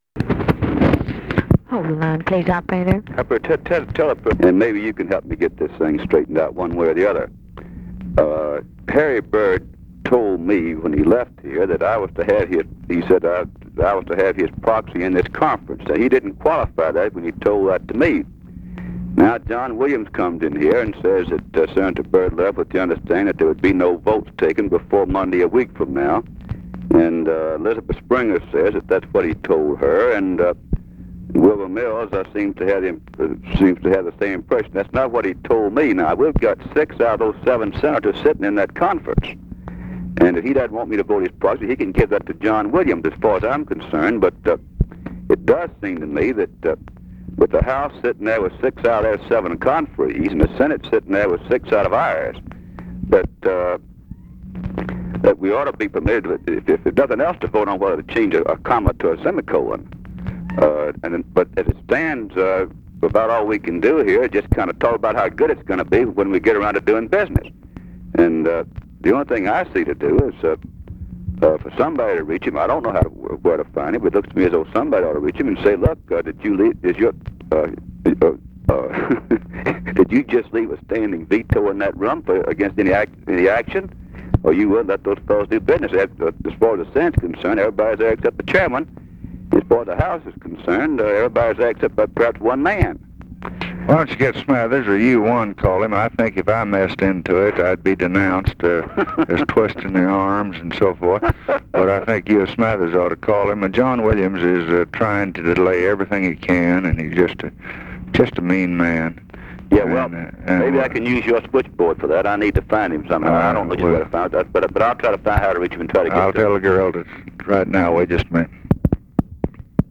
Conversation with RUSSELL LONG, February 10, 1964
Secret White House Tapes